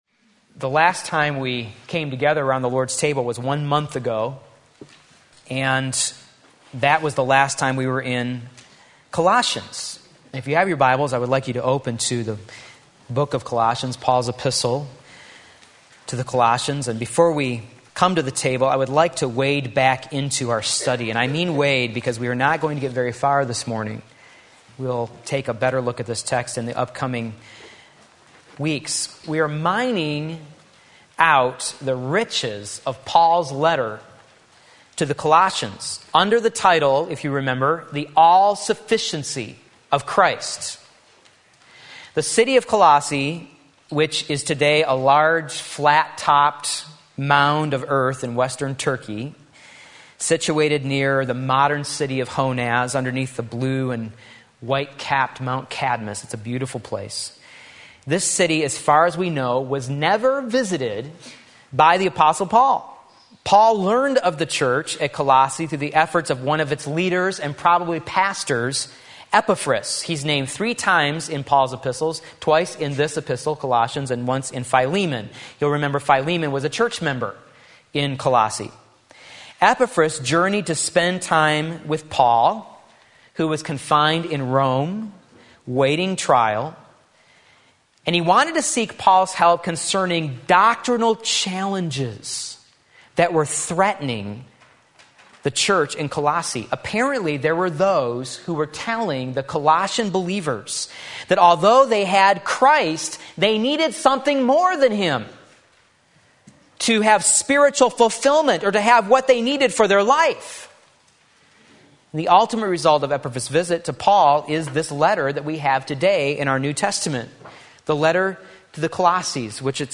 Sermon Link
The Two Creations Colossians 1:15-20 Sunday Morning Service